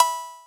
Bell [5].wav